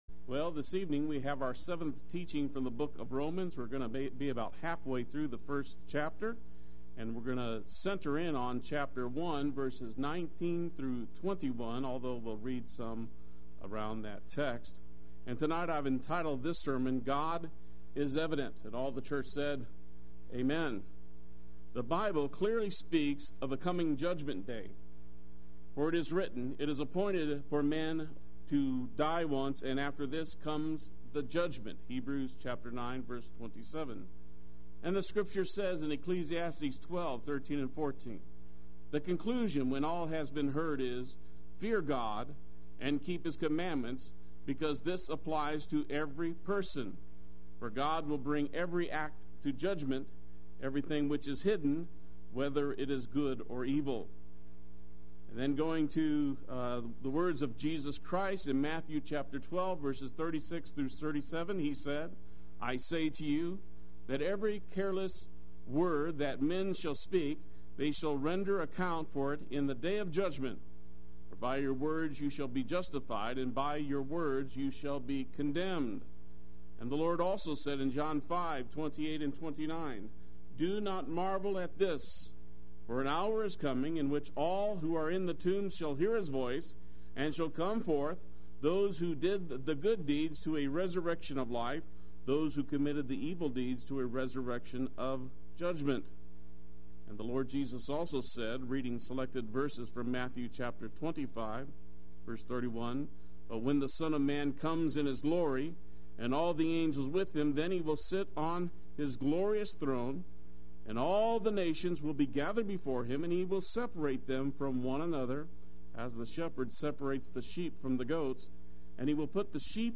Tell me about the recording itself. God is Evident Wednesday Worship